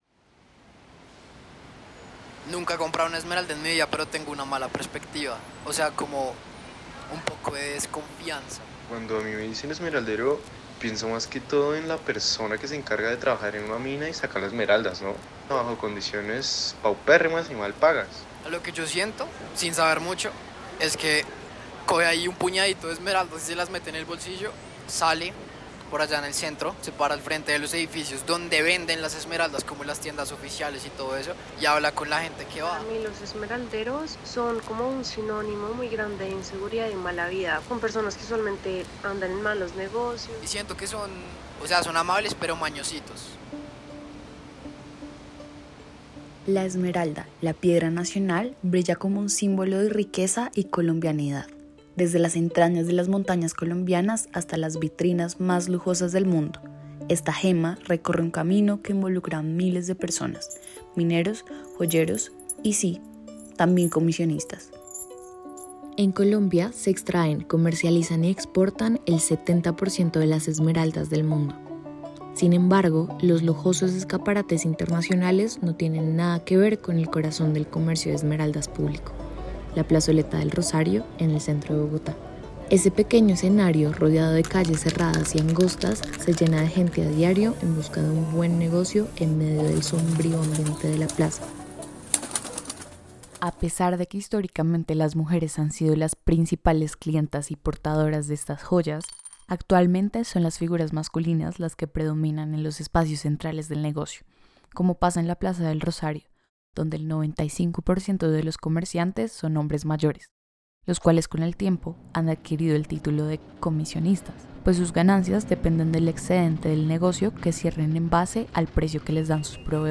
A través de los sonidos de la plaza y sus voces, se revela el contraste entre la opulencia de las esmeraldas y la lucha diaria de quienes viven de su comercio.